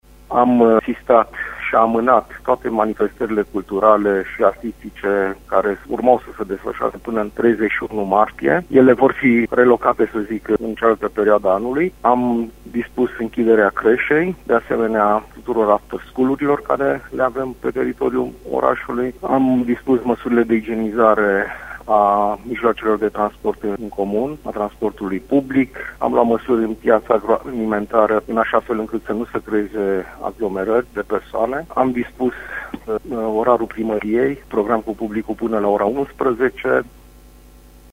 Transportul în comun precum și programul de lucru cu publicul al primăriei sunt și ele modificate, arată primarul mun. Sighișoara, Ovidiu Mălăncrăvean: